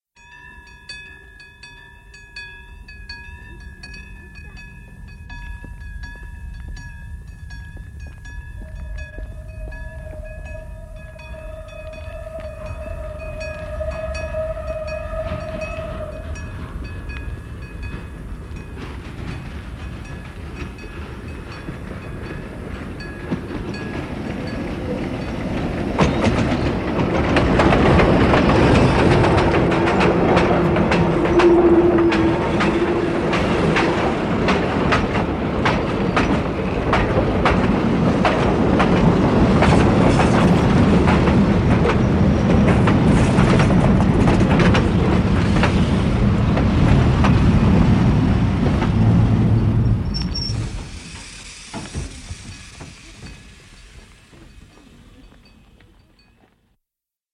Zvukové efekty 2. (Parní lokomotívy, el. vlaky, letadla a motory) audiokniha
Audiokniha Zvukove efekty 2. - obsahuje 34 efektů z prostředí parních lokomotiv, el. vlaků, letadel a motorů.
zvukove-efekty-2-parni-lokomotivy-el-vlaky-letadla-a-motory-audiokniha